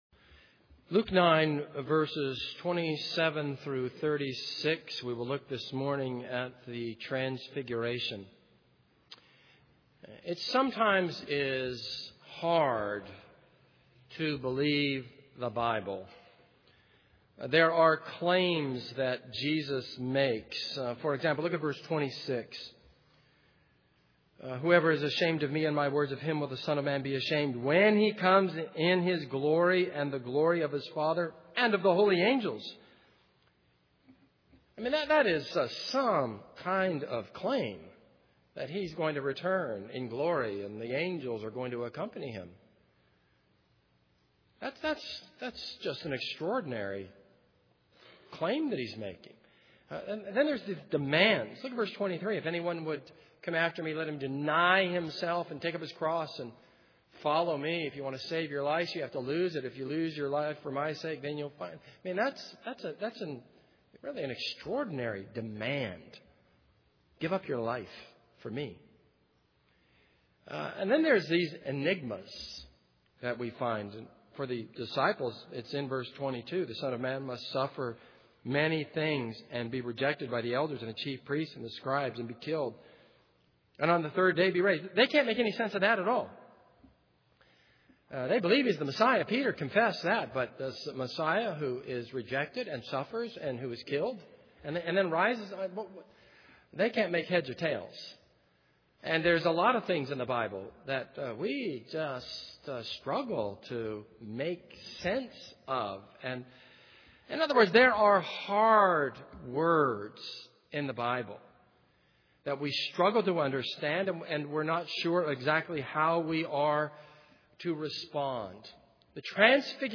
This is a sermon on Luke 9:27-36.